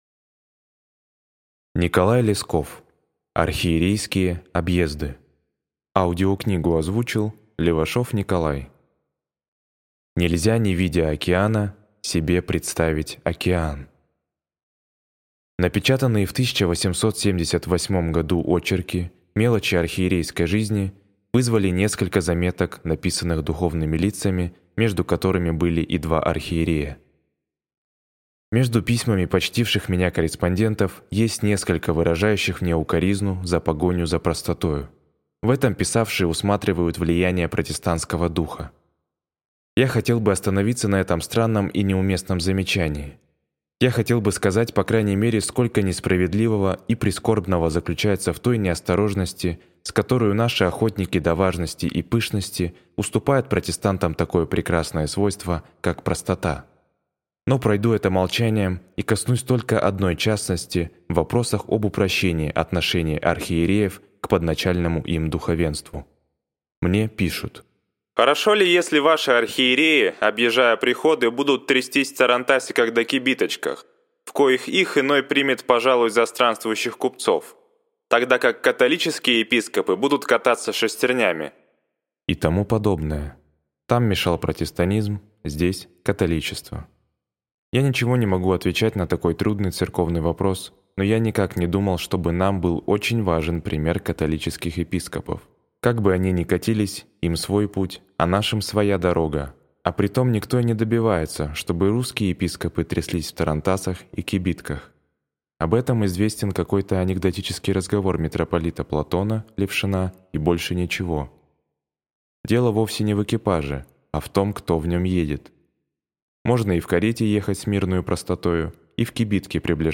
Аудиокнига Архиерейские объезды | Библиотека аудиокниг